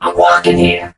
mech_crow_get_hit_03.ogg